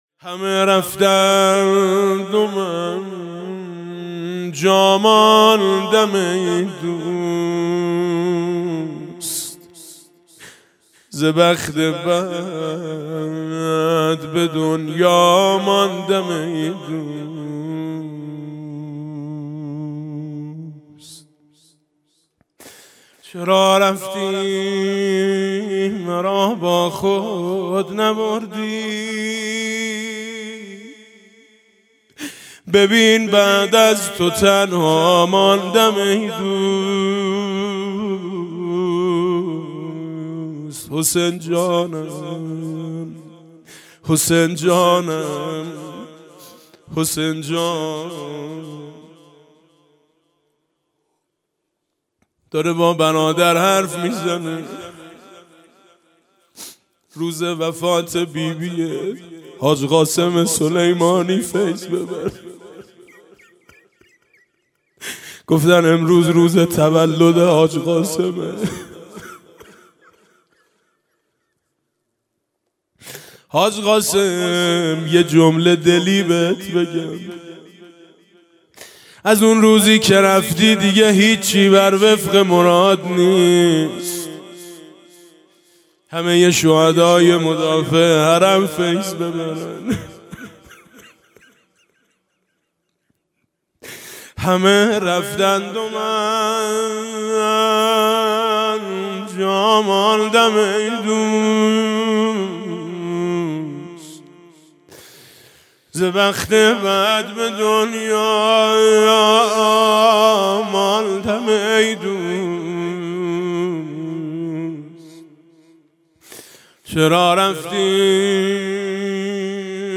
هیأت مجازی | قرائت دعای ام داود و توسل به حضرت زینب سلام‌الله‌...
دعا و زیارت: دعای ام داود حاج میثم مطیعی